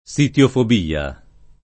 sitiofobia [ S it L ofob & a ]